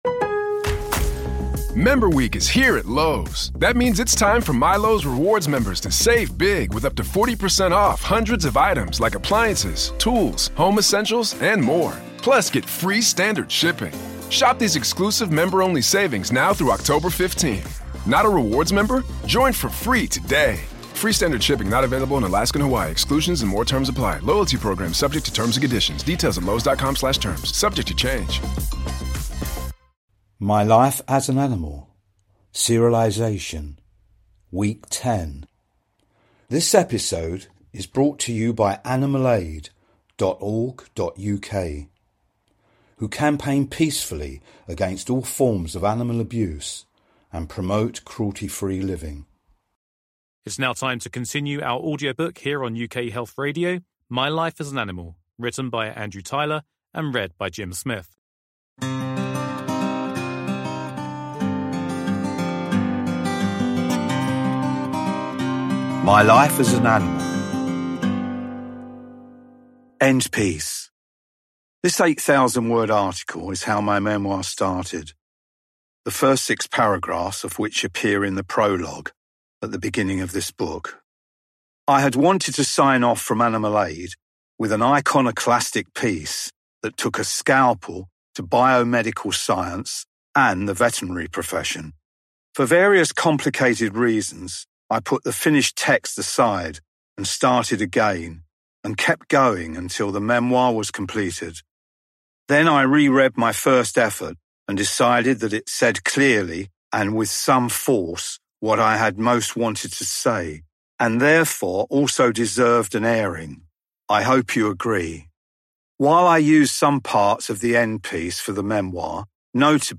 UK Health Radio is running its first ever Book serialisation!
It is beautifully written and sensitively voiced.